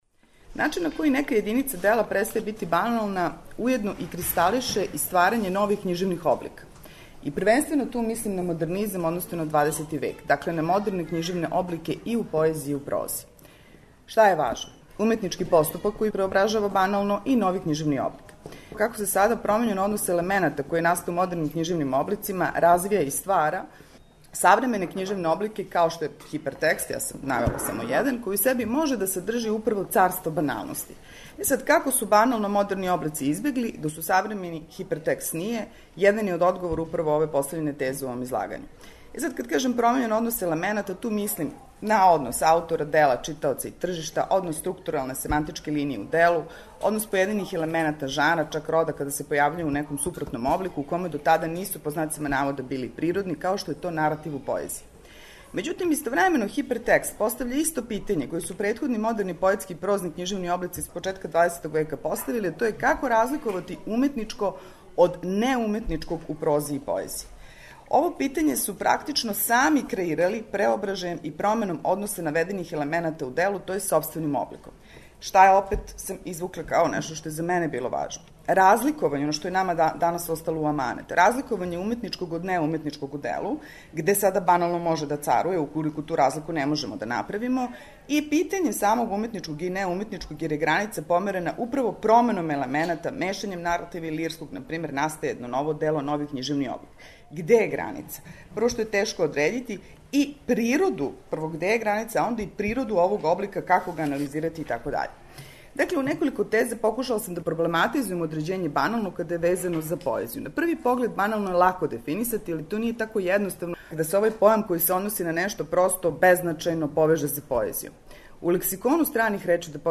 са округлог стола посвећеног теми 'Поезија и баналност', који је одржан 31. августа у Библиотеци града Новог Сада, а у оквиру једанаестог Међународног новосадског књижевног фестивала.